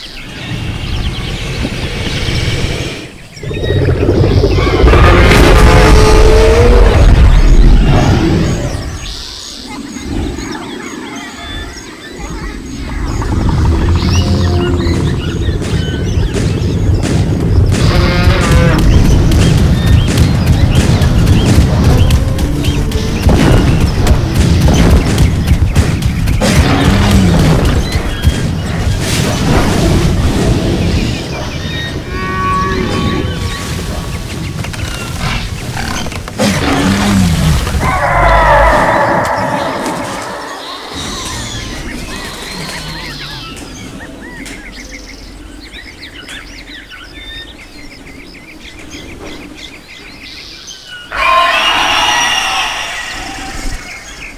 ambiances
death.ogg